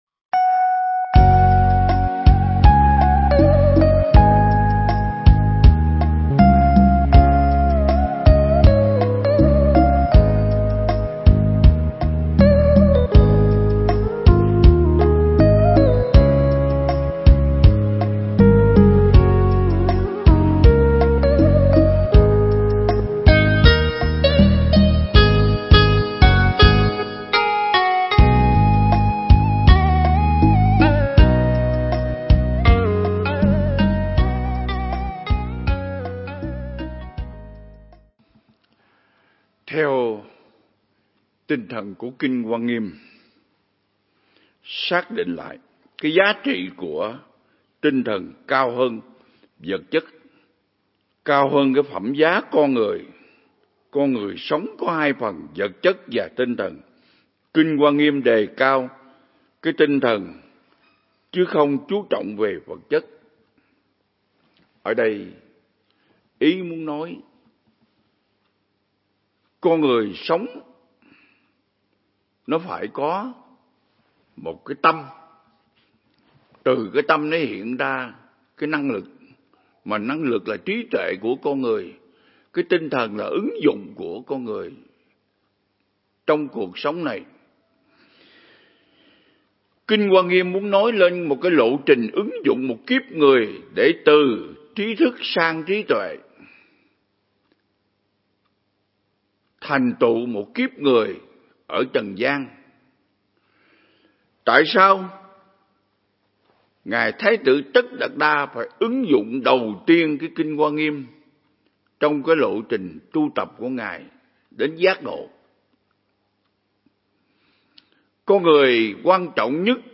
Mp3 Pháp Thoại Ứng Dụng Triết Lý Hoa Nghiêm Phần 15
giảng tại Viện Nghiên Cứu Và Ứng Dụng Buddha Yoga Việt Nam (TP Đà Lạt)